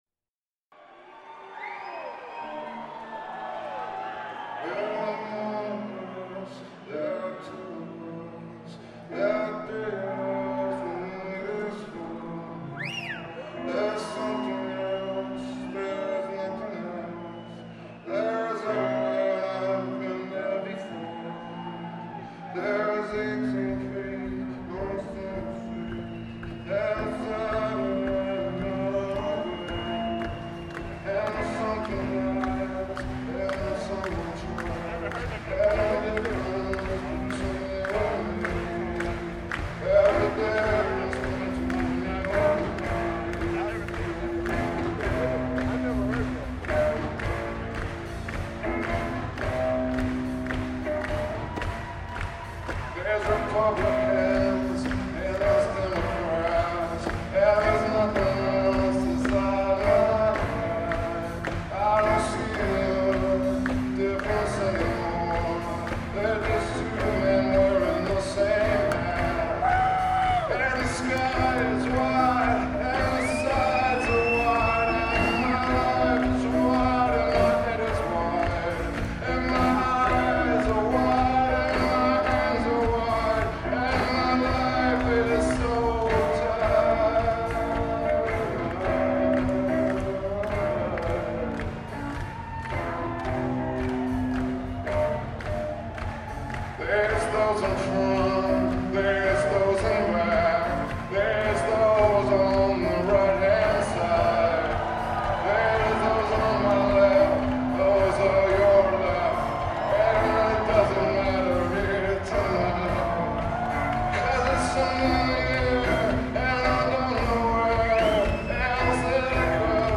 08/04/00 - Blockbuster Pavilion: Charlotte, NC